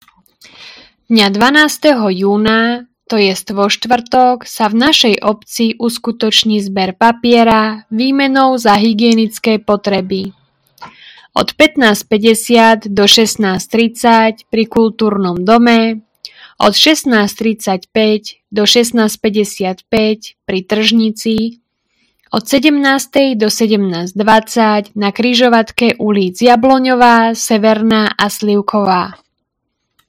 Hlásenie obecného rozhlasu – Zber papiera 12.06.2025